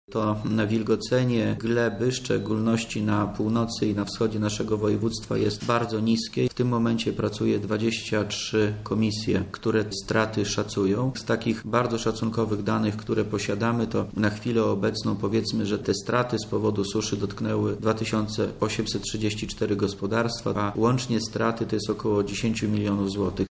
– Jesteśmy jednym z czterech województw, w których nie ogłoszono suszy – mówi wojewoda lubelski Wojciech Wilk